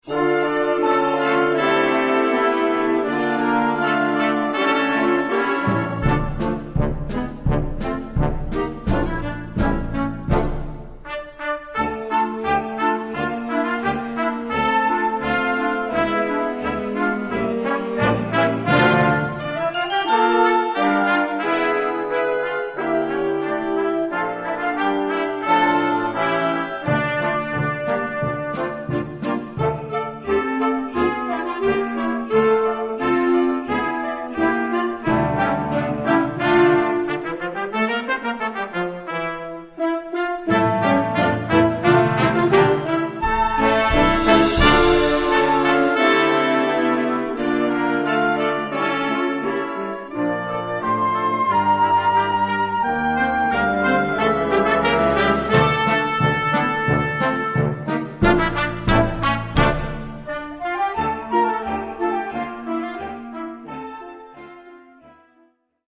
Gattung: Weihnachtliche Blasmusik
Besetzung: Blasorchester